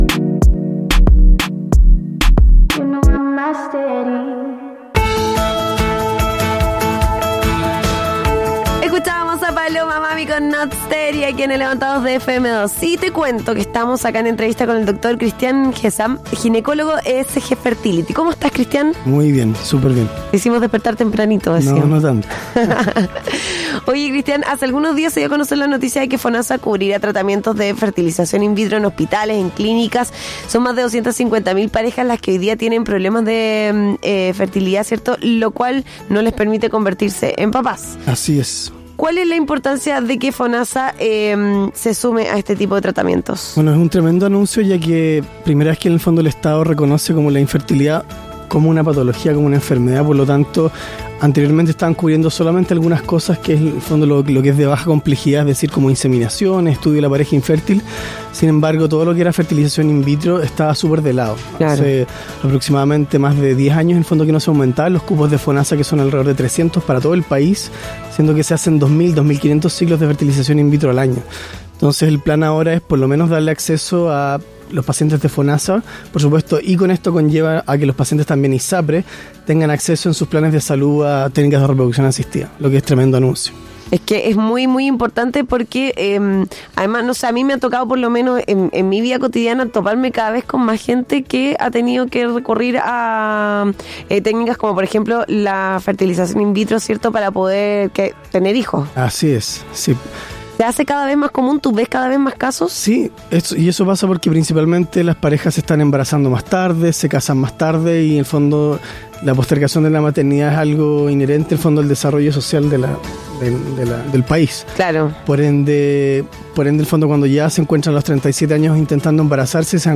Además cuenta sobre los tratamientos como la Fecundación In Vitro, Ovodonación y Preservación de Fertilidad en el programa Levantados de FM2.